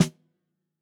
TUNA_SNARE_4.wav